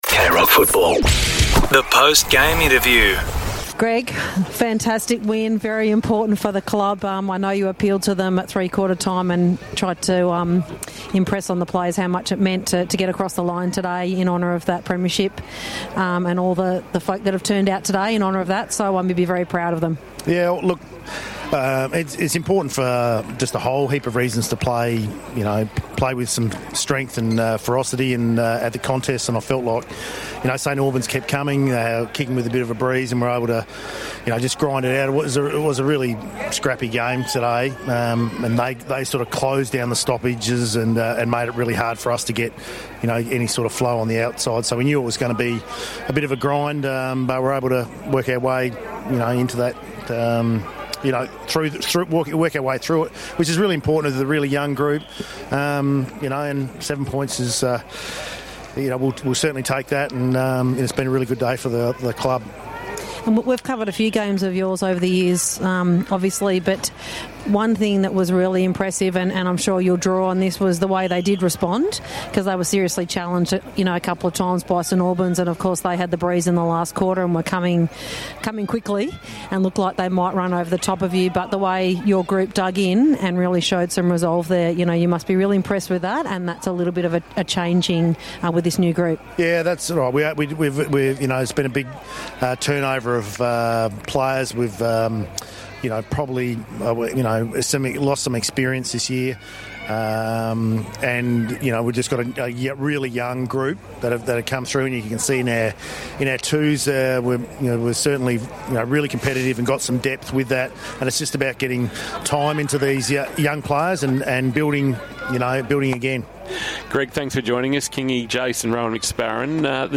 2025 - GFNL - Round 2 - Geelong West vs. St Albans: Post-match interview